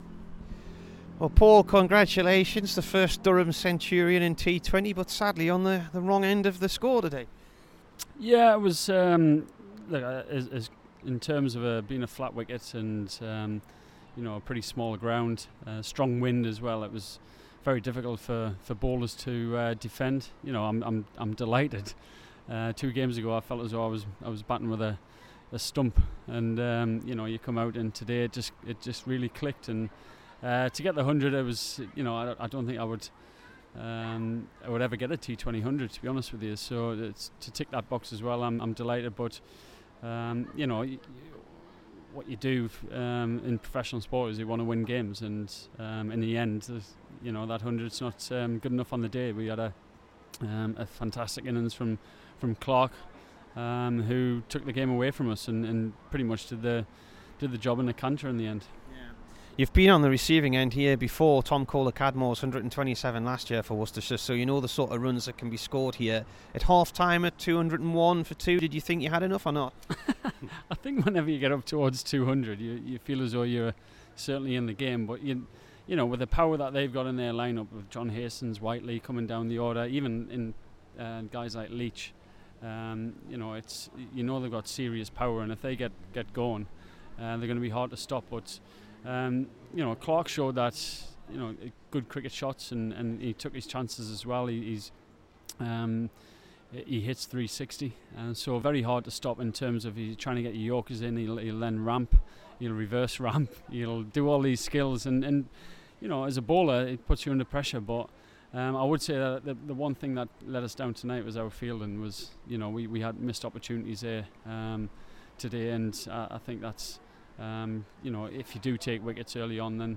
PAUL COLLINGWOOD INT